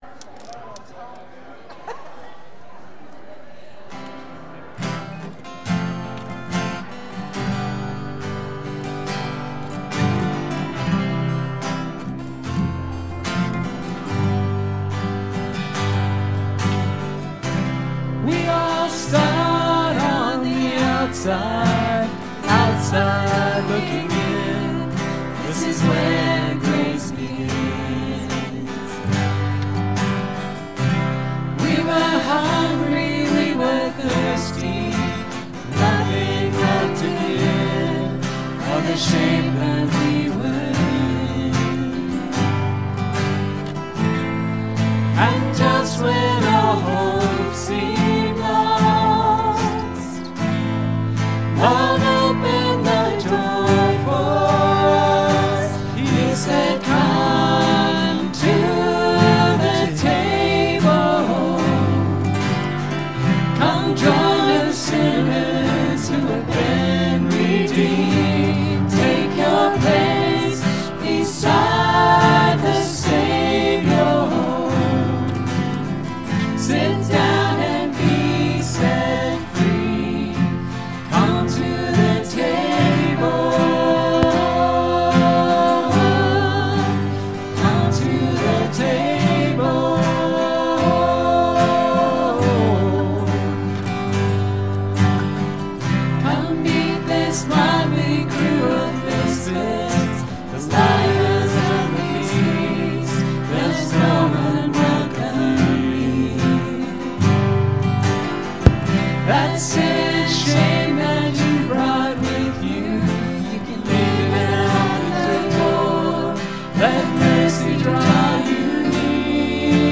Sermons | Enterprise Christian Church